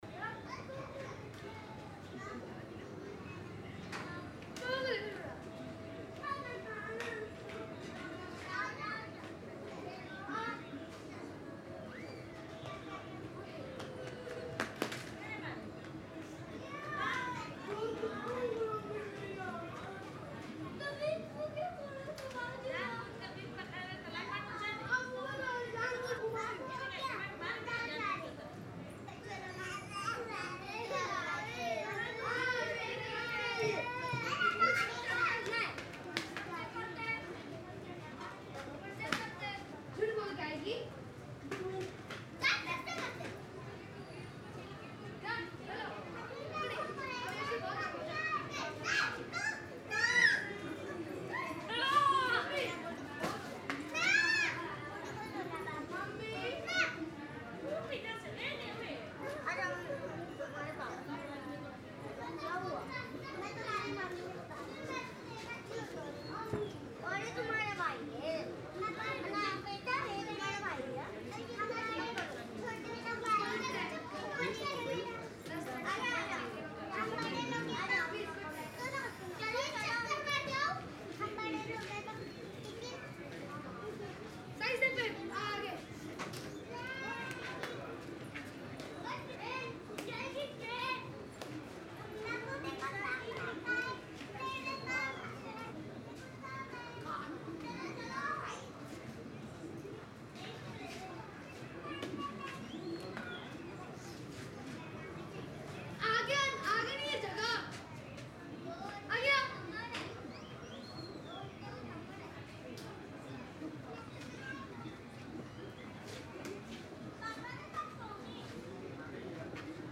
Slumyard People Talking captures the everyday human atmosphere of a densely packed urban settlement. This ambience blends casual conversations, distant chatter, children’s voices, utensil movements, soft footsteps, light laughter, and natural community background noise. The texture feels raw, authentic, and emotionally grounded, reflecting real neighbourhood life.
Balanced, natural, and true to real Indian slumyard audio texture.
Human / Urban Ambience
Outdoor Community Field
Natural, Busy, Realistic
People Talking / Community
Thirtyeight-slumbackyard-people-talking.mp3